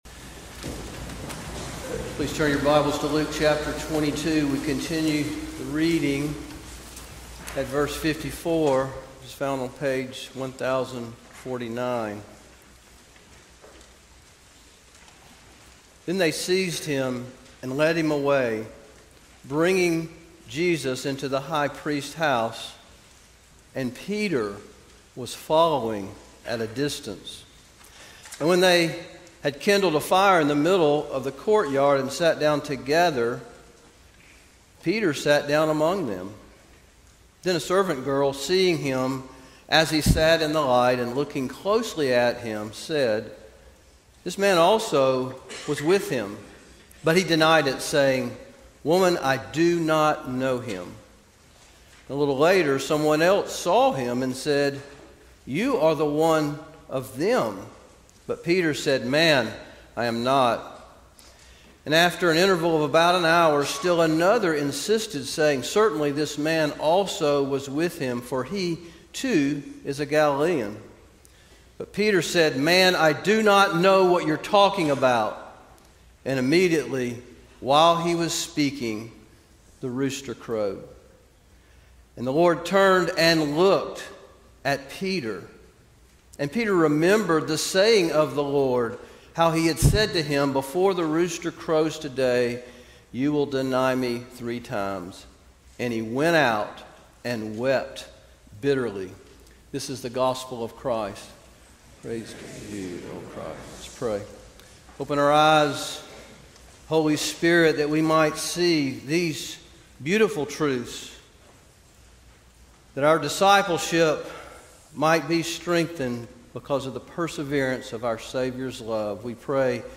Sermons - First Presbyterian Church of Augusta
Luke 22:54-62 March 29, 2024 Good Friday Evening